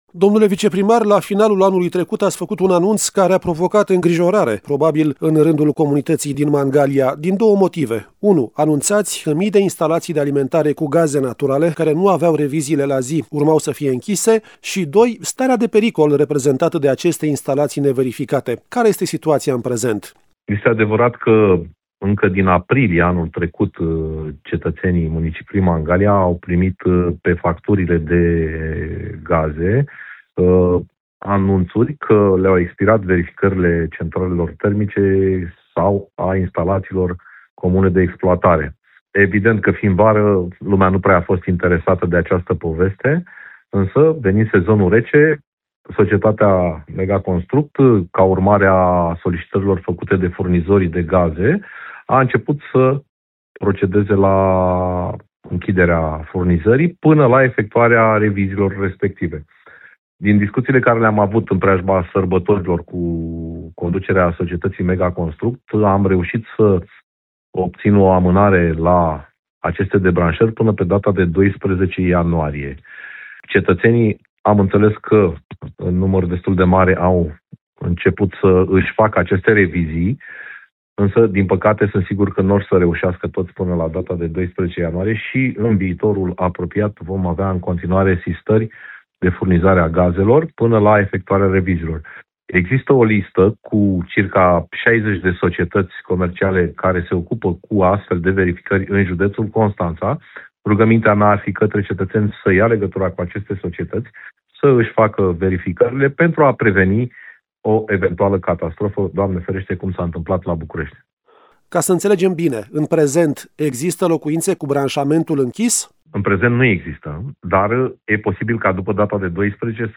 a discutat subiectul cu Paul Foleanu, viceprimarul cu atribuții de primar al municipiului Mangalia.